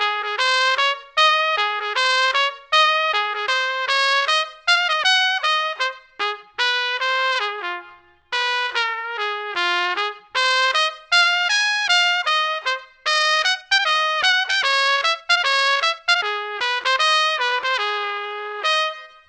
Given a MIDI score and an audio recording of an example piece of monophonic music, our method synthesizes audio to correspond with a new MIDI score.
This set of results was obtained using an algorithm which concatenates single notes from the example piece to form the result.
trumpet16_guitar_good.wav